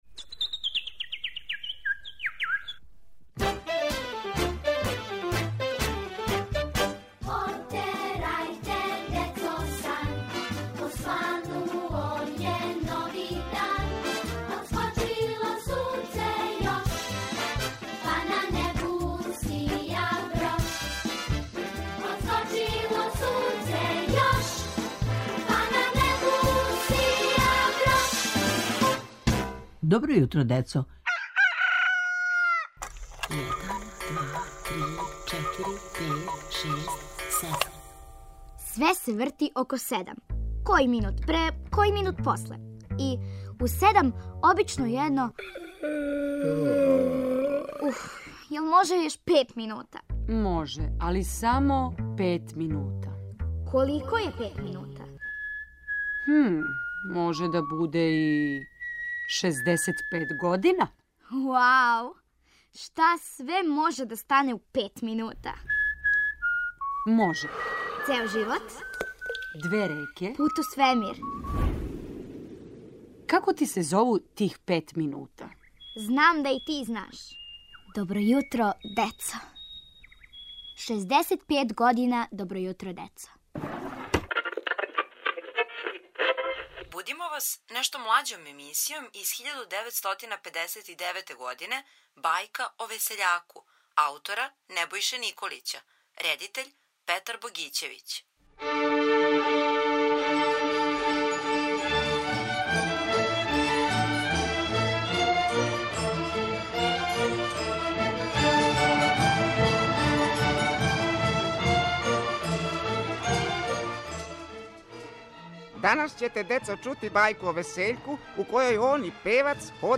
Поводом 65. рођендана емисије "Добро јутро децо", будимо вас емисијом из старе фиоке "Бајка о весељаку".